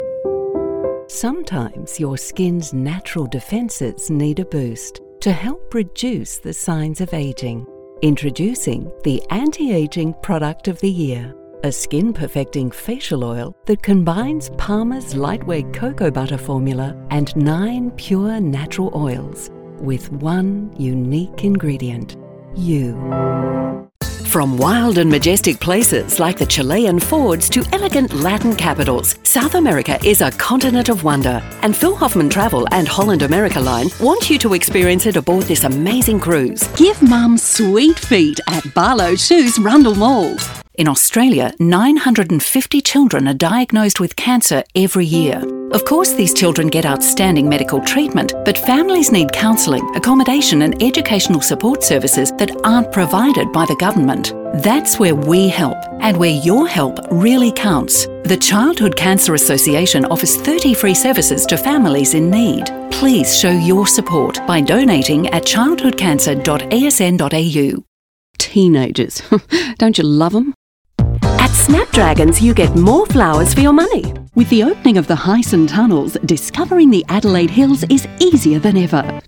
Female
English (Australian)
Radio / TV Imaging
Words that describe my voice are sensual, conversational, authoritative.
All our voice actors have professional broadcast quality recording studios.
02182._Radio_TV_Style.mp3